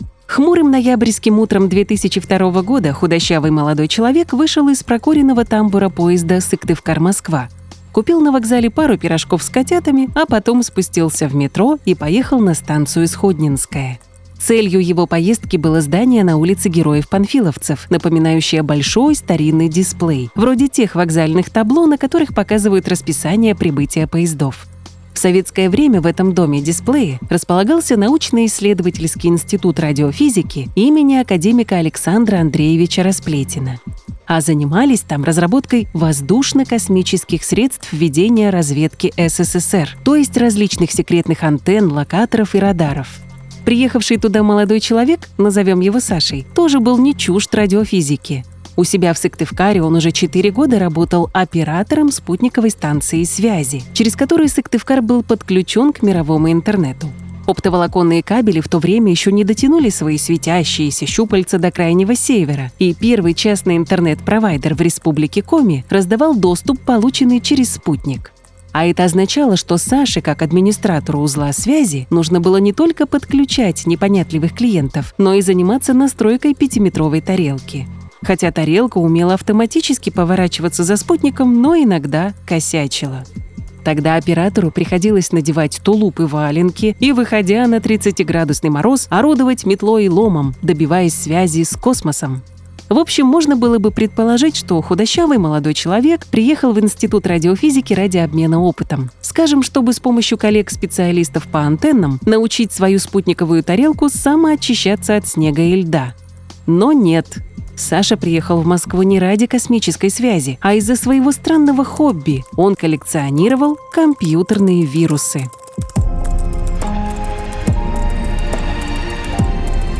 Документально-разговорный подкаст о кибербезопасности.